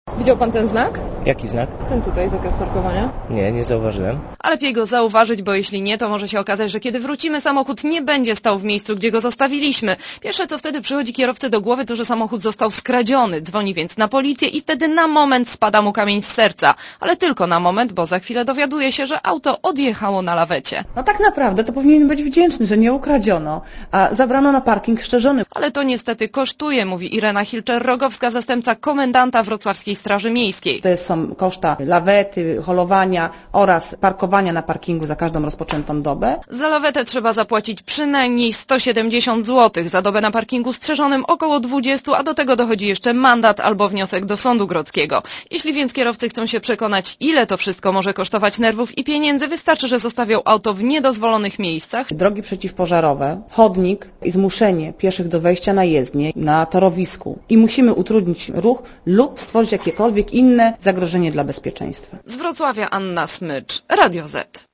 Posłuchaj relacji reporterki Radia Zet (258 KB)